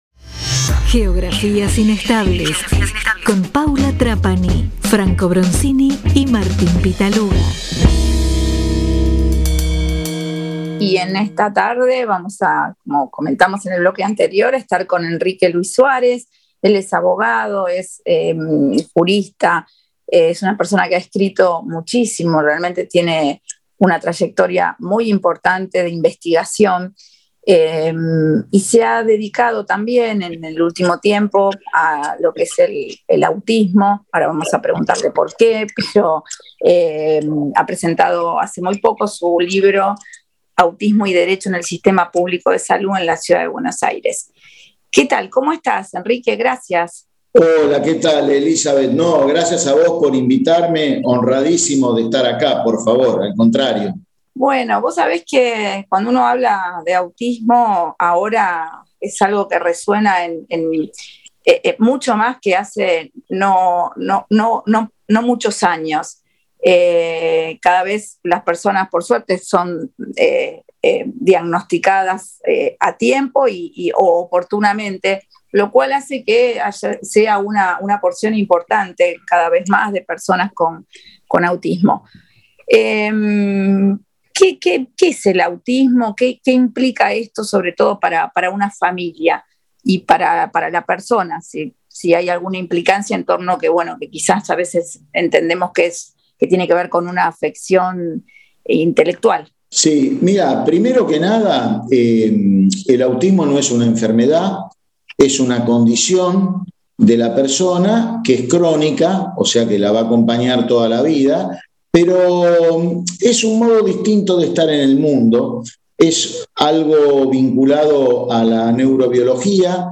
nos trae una gran entrevista.